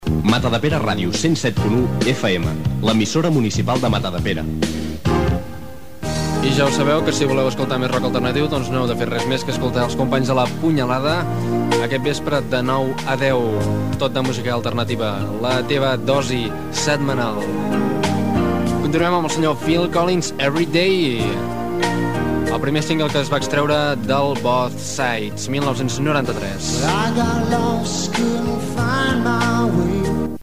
Indicatiu de l'emissora, recomanació del programa "La punyalada" i tema musical
FM